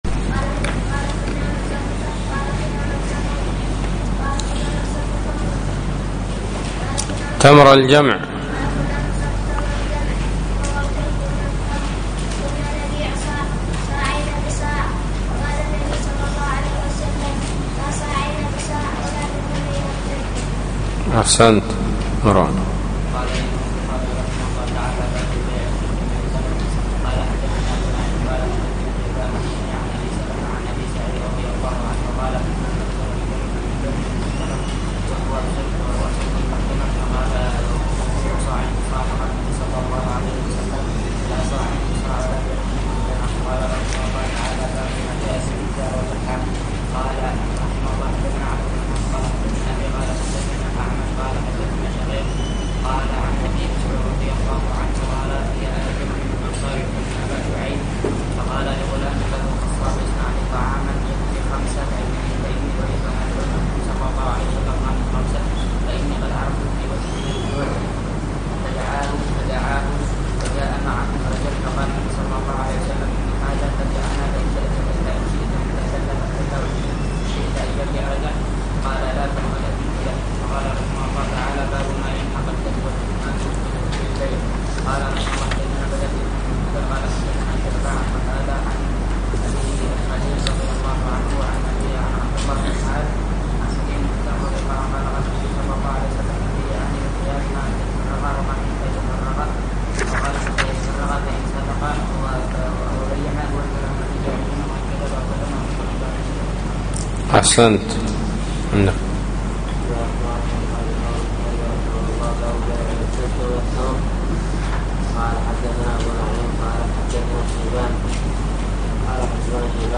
الدرس العشرون : بَاب: قَوْلِ اللَّهِ تَعَالَى: يَأَيُّهَا الَّذِينَ آمَنُوا لا تَأْكُلُوا الرِّبَا أَضْعَافًا مُضَاعَفَةً وَاتَّقُوا اللَّهَ لَعَلَّكُمْ تُفْلِحُونَ